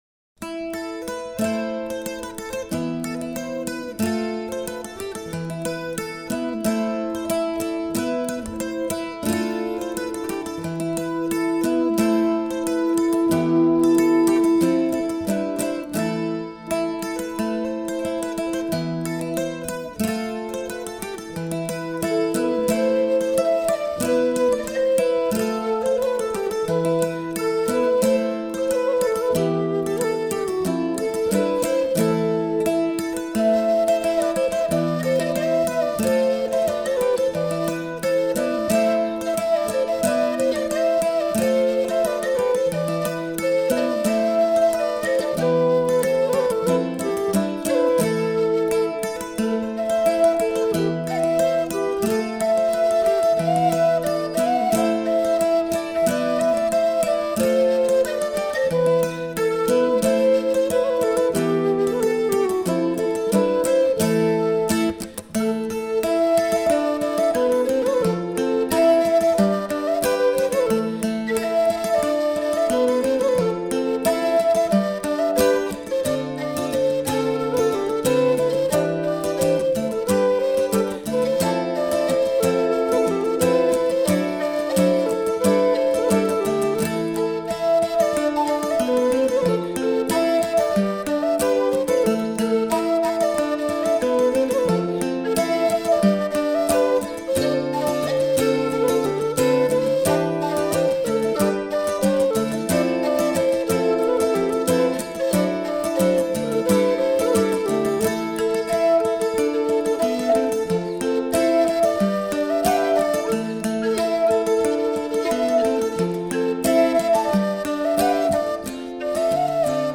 Erledanz joue pour le Bal Folk.